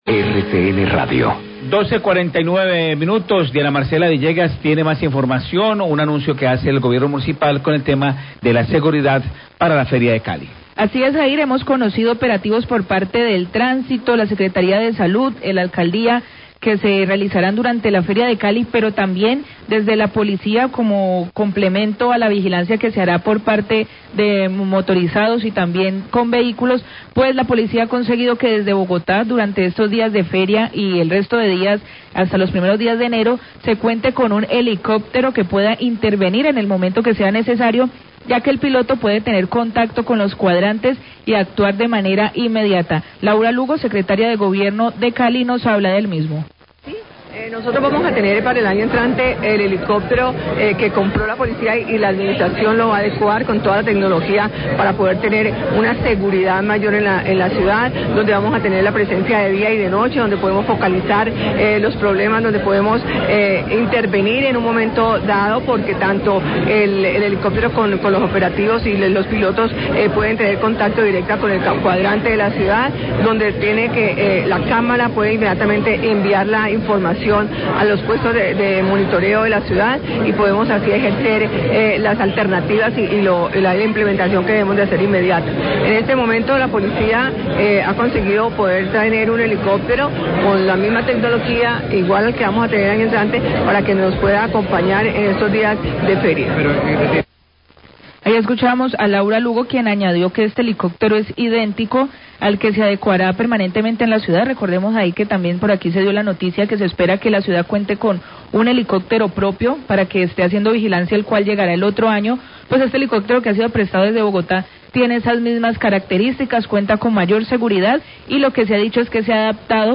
Radio
El Secretario de Gestión de Riesgo, Rodrigo Zamorano, habla de los planes de contingencia para los días de Feria.